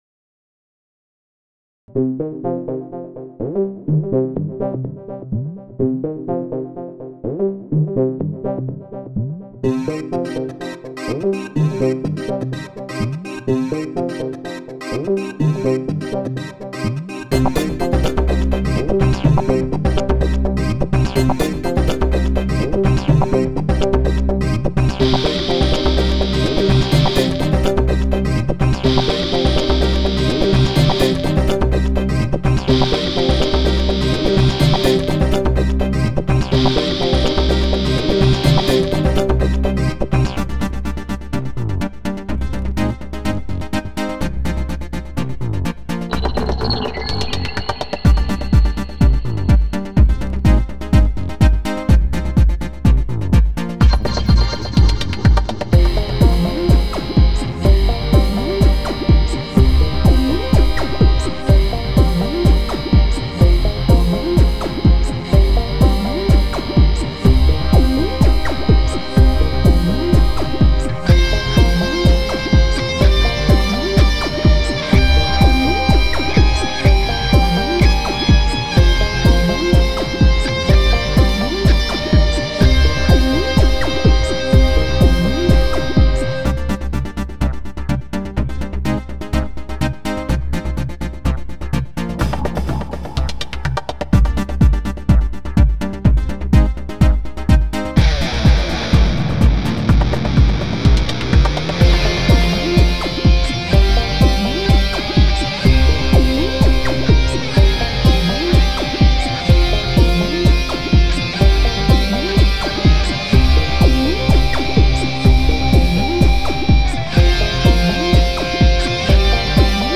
I have done a few dance beat tunes also
It's a bunch of short synth riffs cut and pasted together